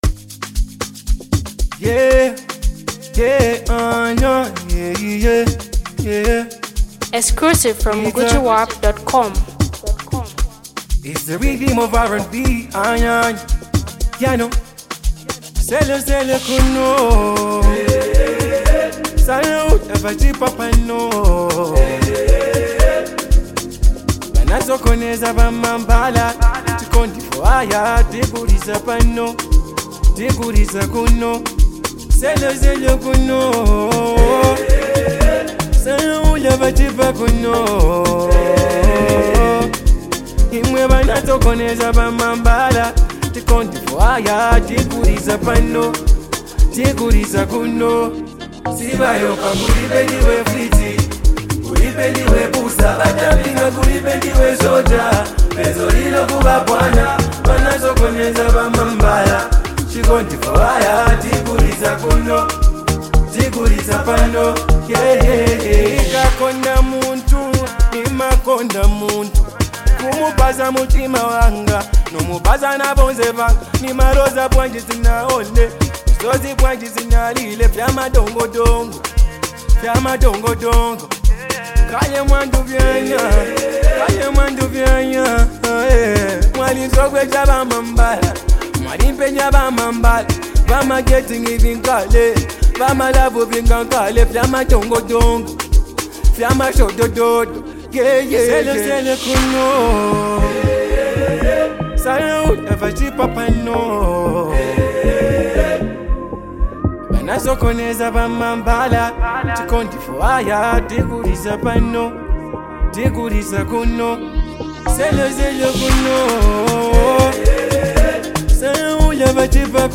” a soulful new track that showcases his vocal prowess.
this song blends heartfelt lyrics with infectious rhythms.